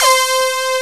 RAPSYNHIT1.wav